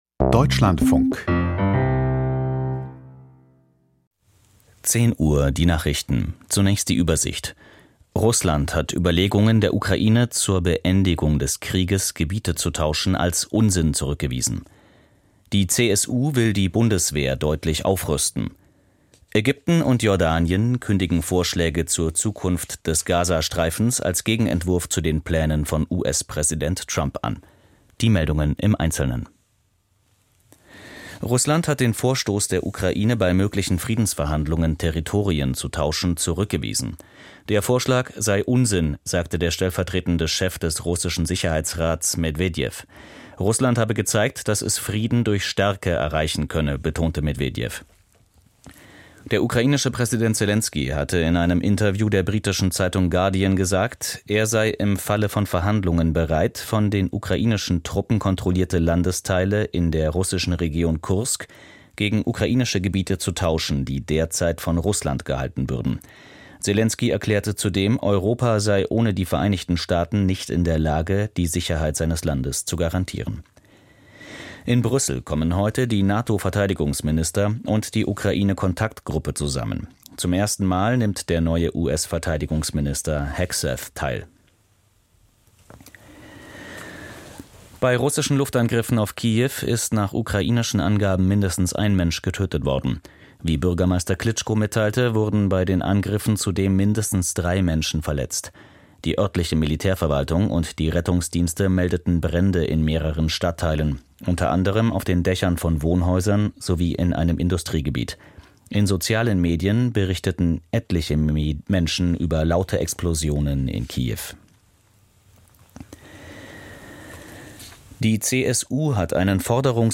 Die wichtigsten Nachrichten aus Deutschland und der Welt.